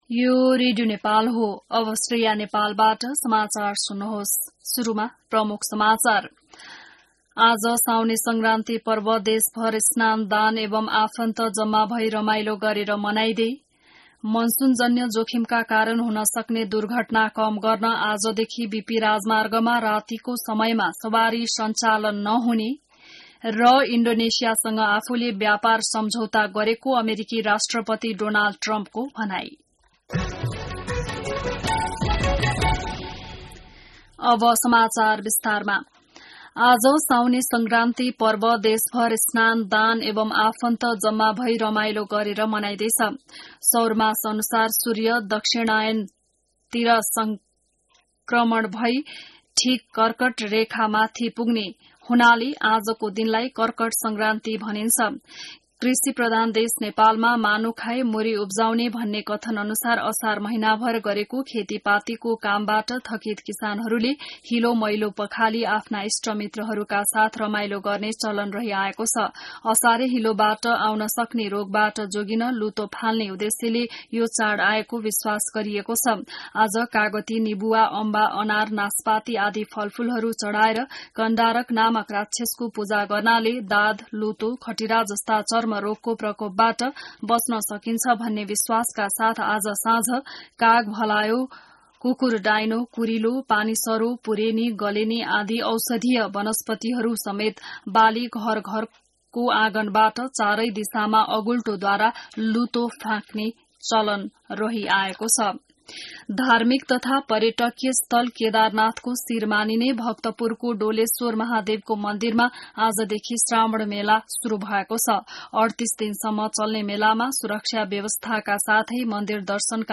बिहान ९ बजेको नेपाली समाचार : १ साउन , २०८२